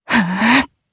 Lewsia_B-Vox_Casting3_jp_b.wav